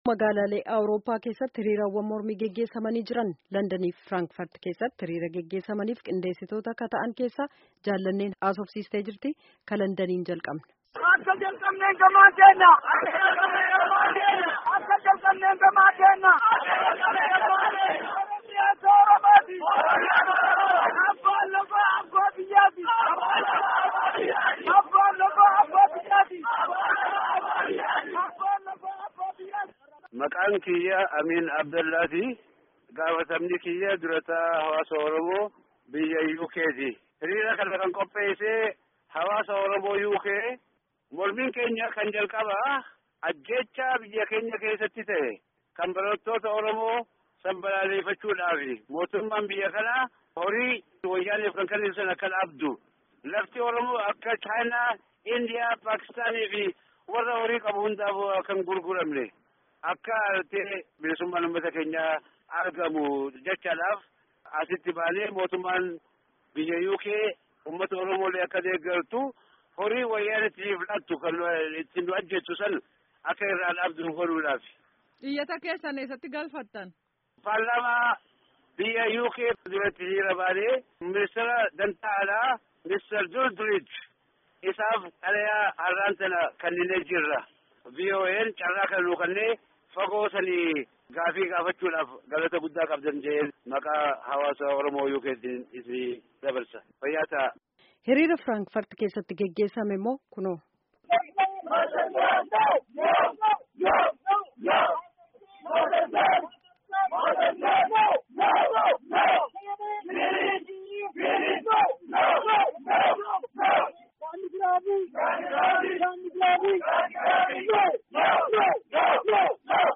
Gabaasa Hiriira London fi Frankfurt keessaa